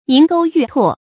銀鉤玉唾 注音： ㄧㄣˊ ㄍㄡ ㄧㄩˋ ㄊㄨㄛˋ 讀音讀法： 意思解釋： 比喻他人的書法筆畫有如銀鉤，遒勁有力；言談有如吐玉，彌足珍貴。